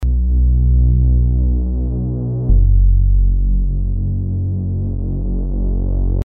Ein Problem dass mir immer wieder begegnet ist das manche Synths (in diesem Fall der Subtractor) ein mir bislang rätselhaftes Eigenleben führen und bei jedem Anschlag den Sound verändern, was mir vor allem bei Bässen auffällt.